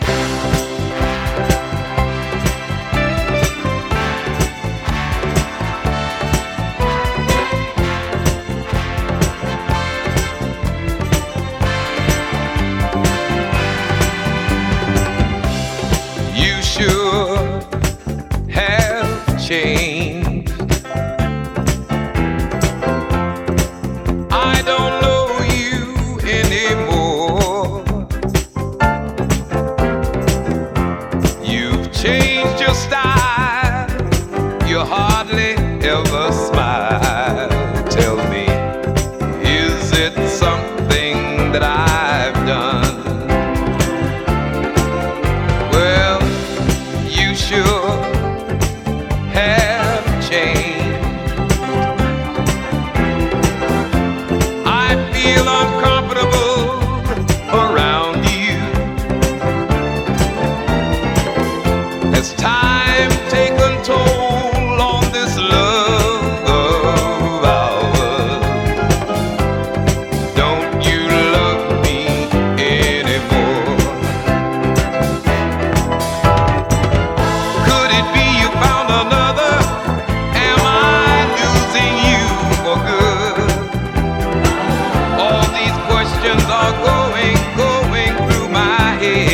スウィートなメロディーに、ストングスなどを多用したドラマティックなアレンジ。
独特のファルセットも最高に泣けます、、。